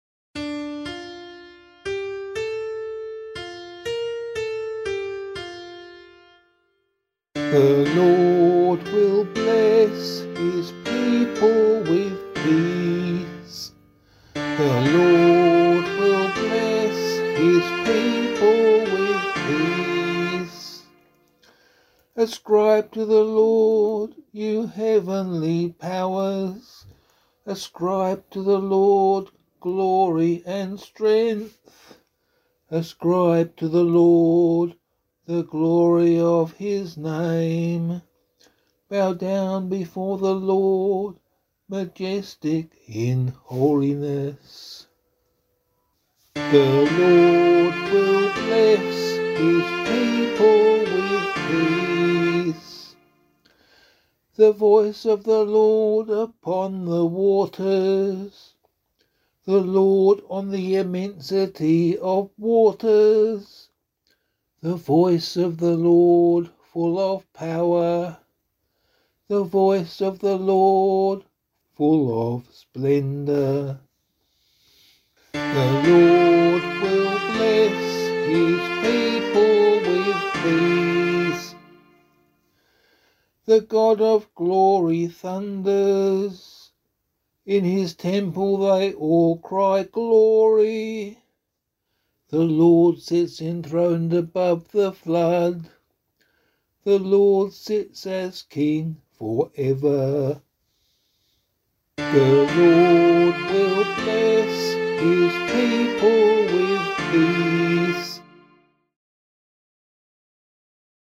011 Baptism of the Lord Psalm A [APC - LiturgyShare + Meinrad 1] - vocal.mp3